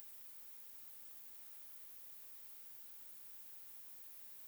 Skillnaden är att bruset är högre i filen som konverterats fram och tillbaka till/från 8 bitar. Om man spelar båda filerna tillsammans och fasvänder den ena får man fram skillnaden.
Det lät som litet tystlåtet tomtebrus